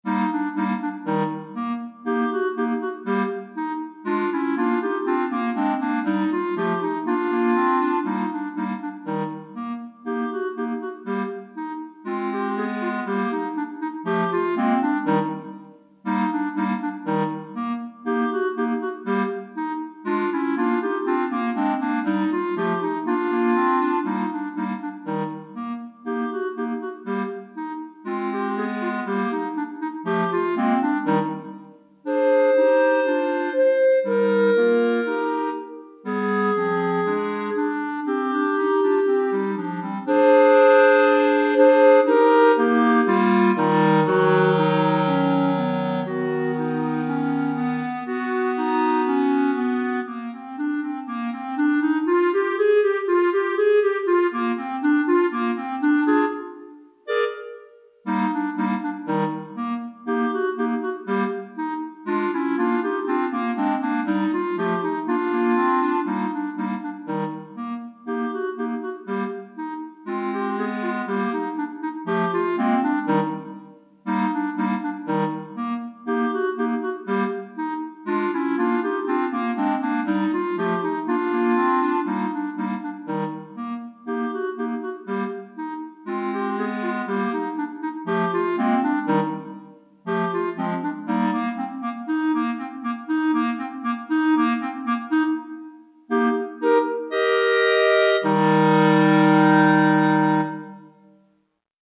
3 Clarinettes en Sib